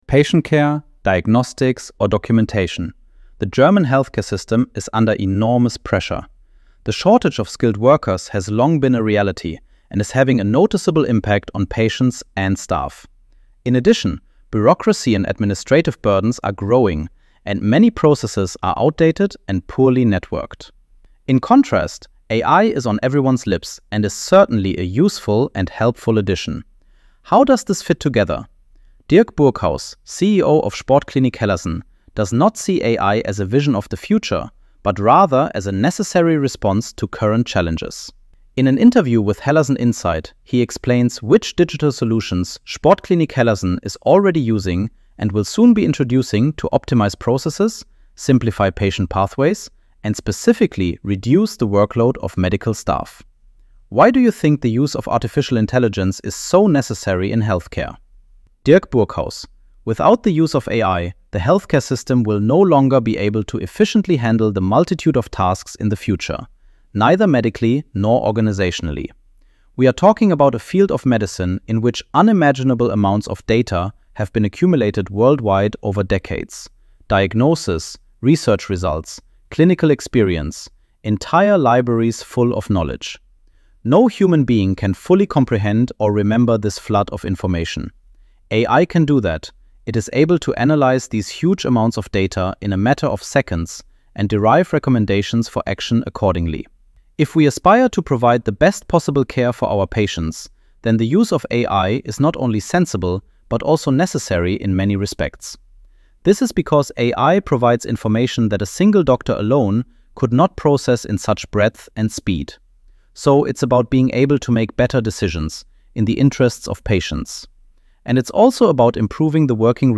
Have article read aloud ▶ Play audio Patient care, diagnostics, or documentation — the German healthcare system is under enormous pressure.